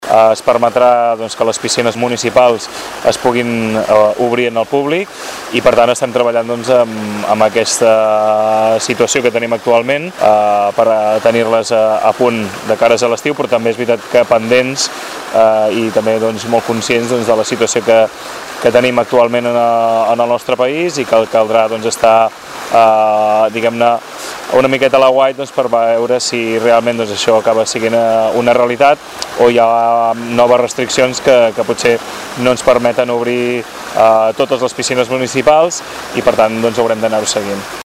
El regidor Pau Megias alerta que caldrà estar pendents de com evoluciona la sequera.